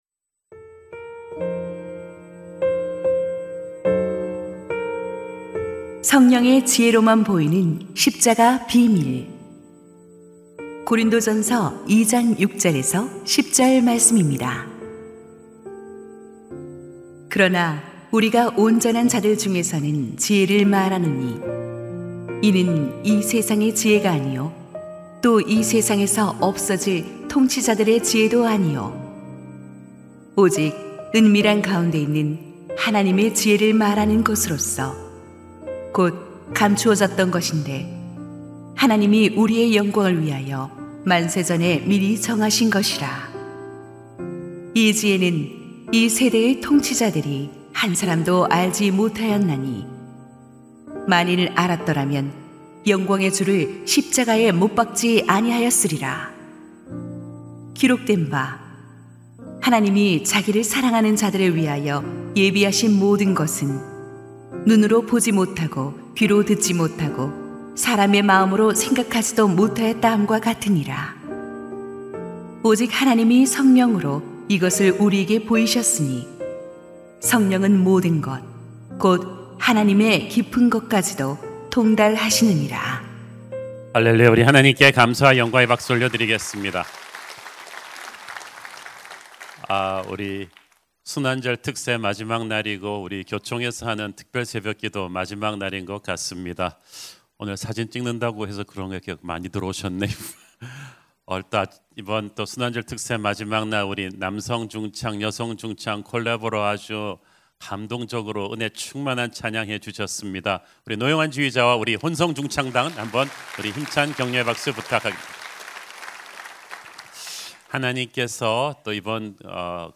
> 설교
[새벽예배] 2026-04-04 고난주간 특별새벽기도회